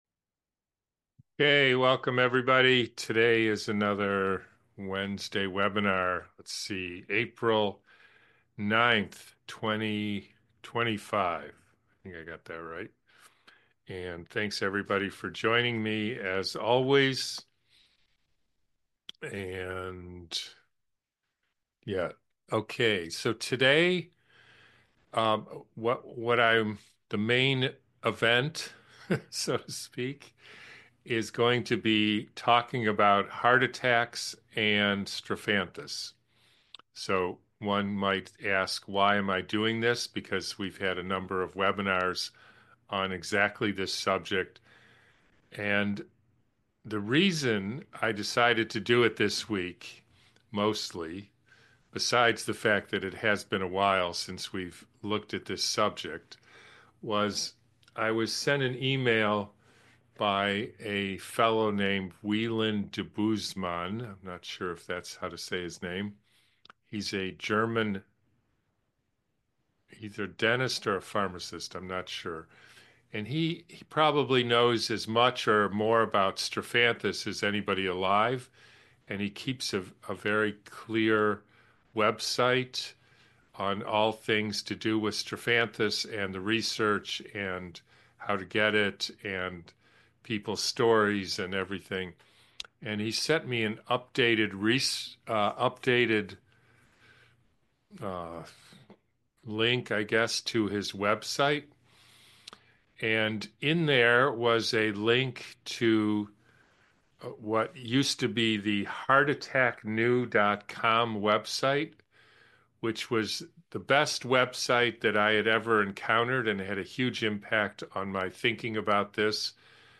heart-attacks-strophanthus-webinar-from-april-9th-2025.mp3